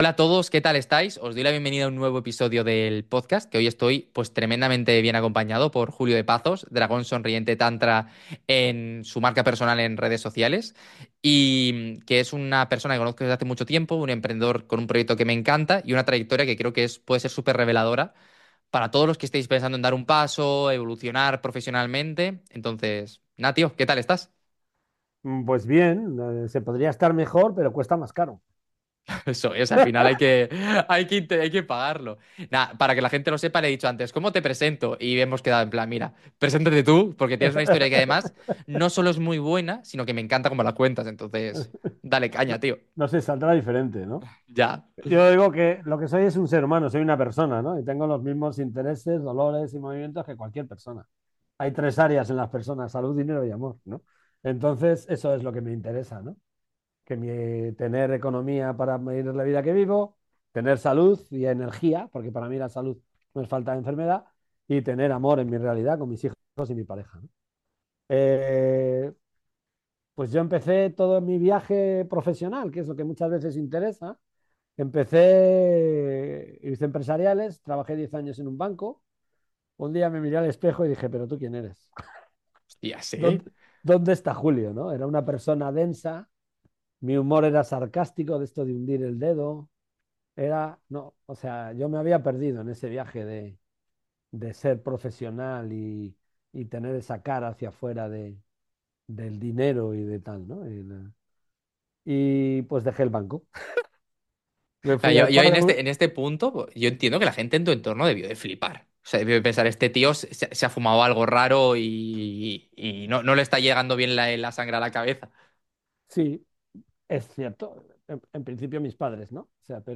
Charlamos con un emprendedor que dejó la banca por emprender un negocio en aquello que de verdad le apasionaba.